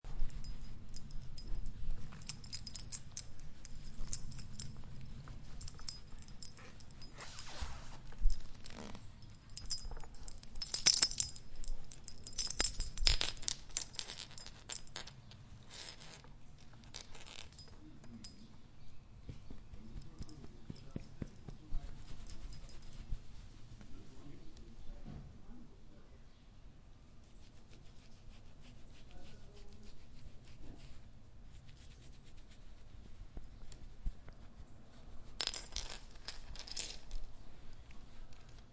Dog Collar Sound Button - Free Download & Play